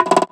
Perc - Packin.wav